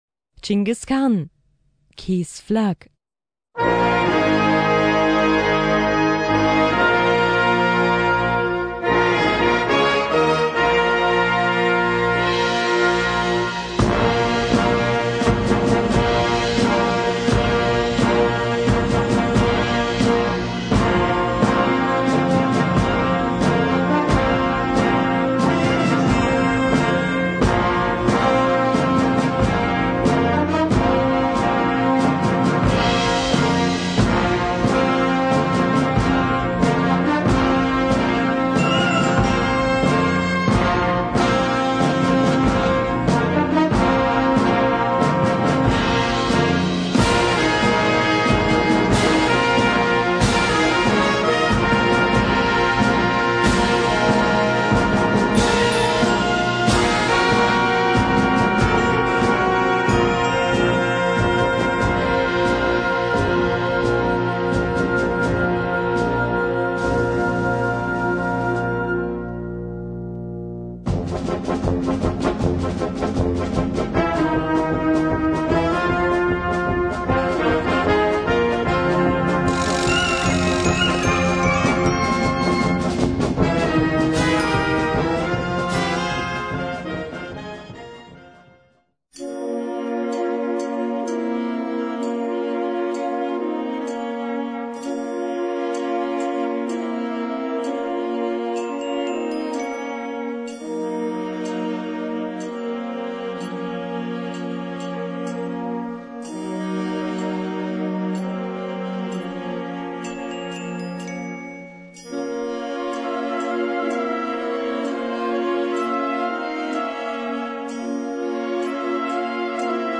Gattung: Ouverture
Besetzung: Blasorchester